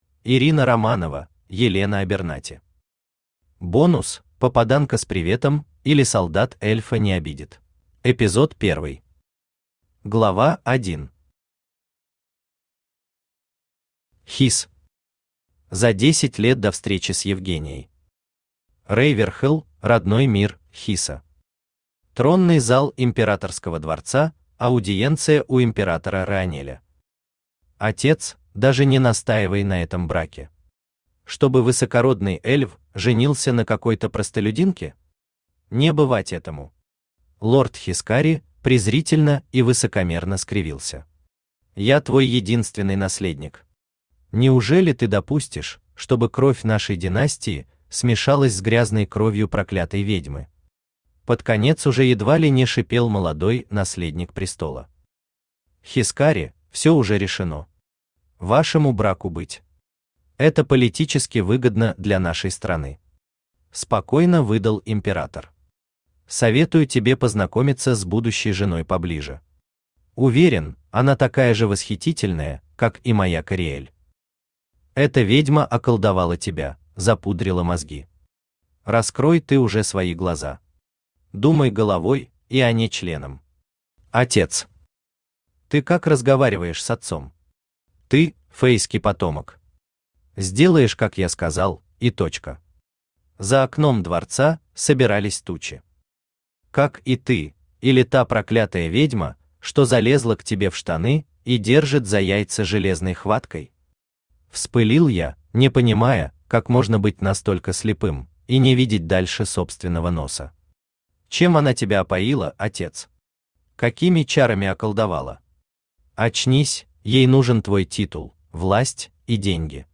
Аудиокнига Попаданка с приветом, или Солдат эльфа не обидит. Бонус | Библиотека аудиокниг
Бонус Автор Ирина Романова Читает аудиокнигу Авточтец ЛитРес.